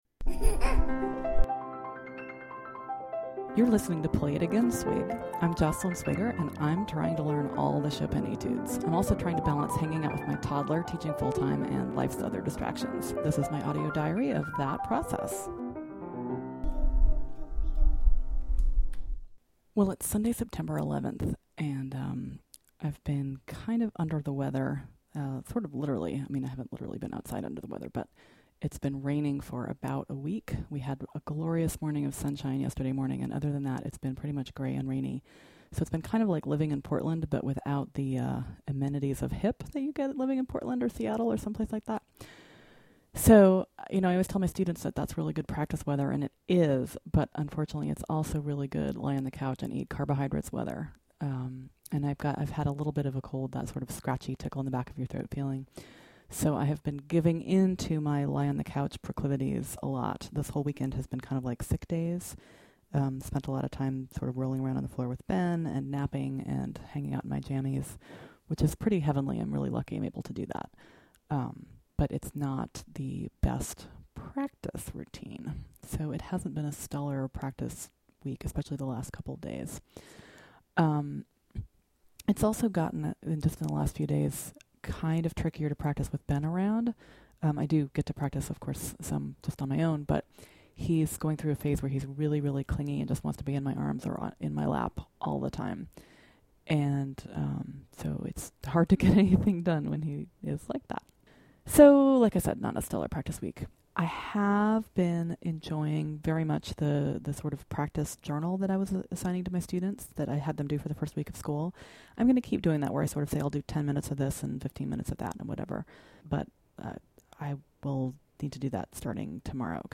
At the end of a weekend of not much practicing, here’s the last Chopin 25/2 for a while; practicing 25/11 in groups; practicing anticipation in 25/3 and 25/5; a little bit of Saint-Saens; an improvisation with a tough goal.